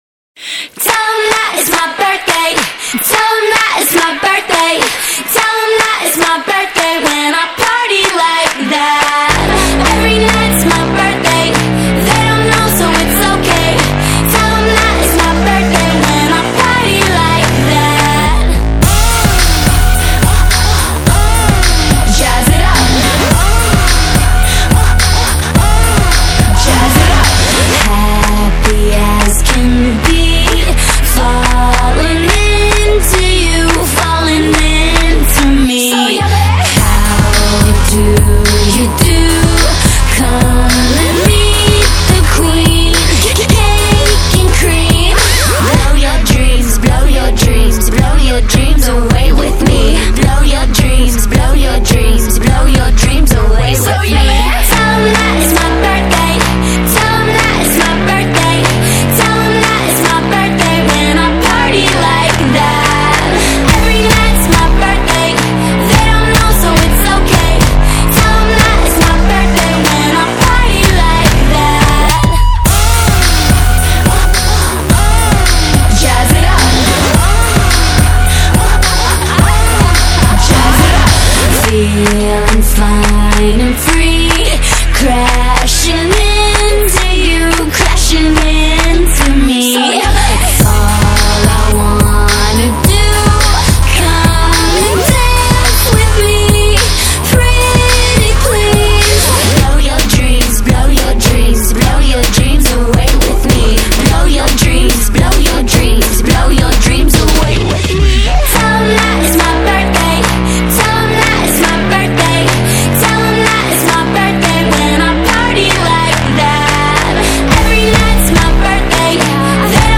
Pop / Dance / Electronic